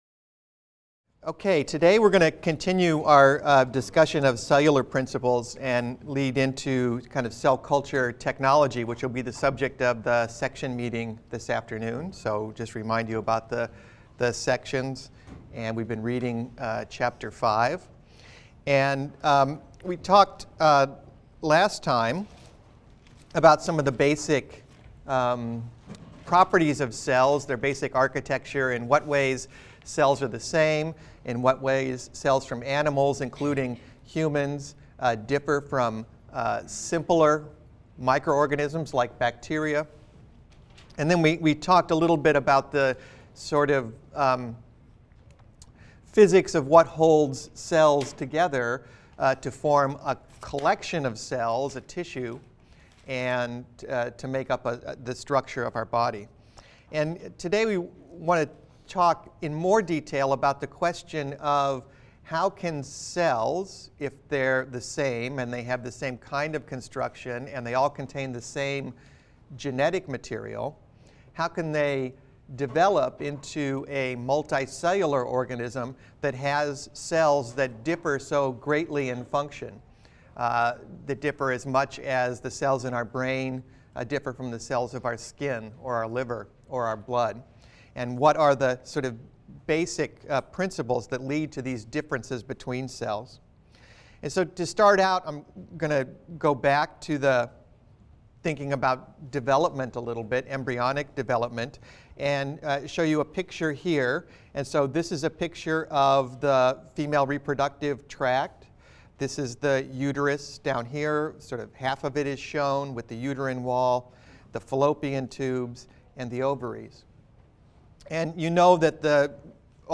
BENG 100 - Lecture 6 - Cell Culture Engineering (cont.)